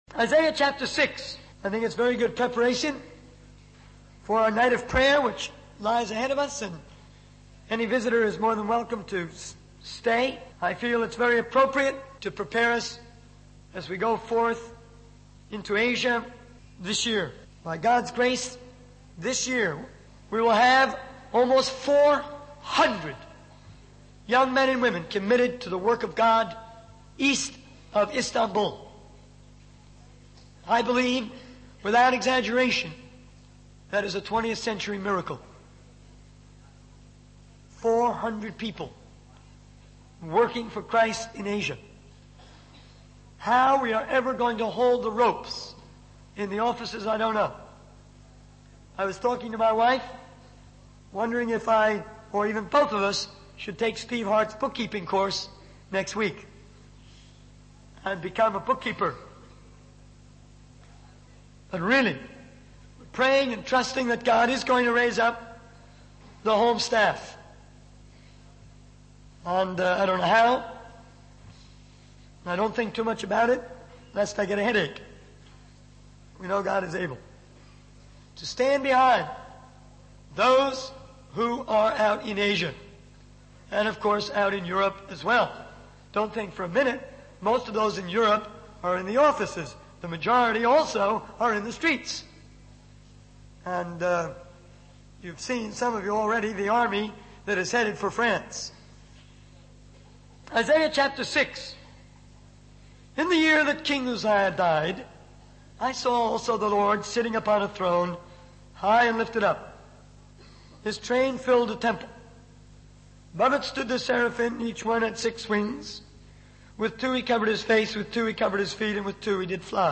In this sermon, the speaker emphasizes the importance of having a vision and praying big prayers. He warns against viewing missionary work as a game or a casual endeavor.